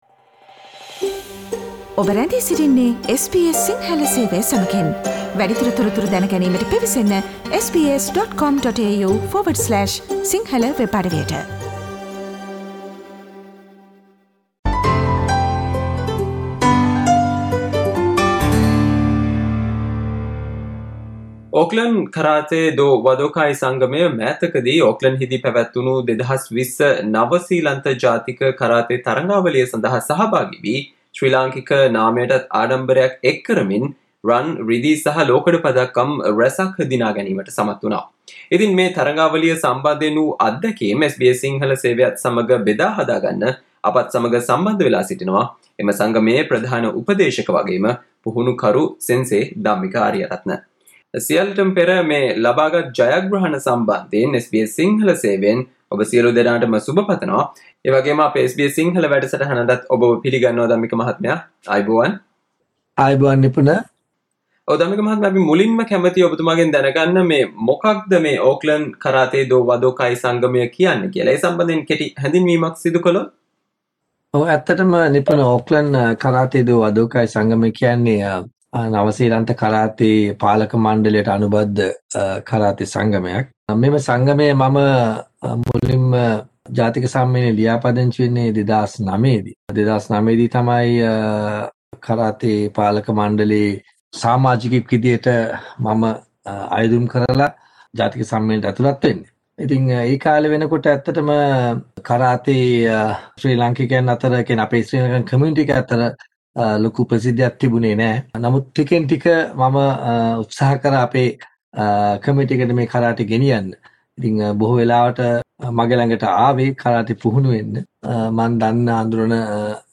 SBS Sinhala discussion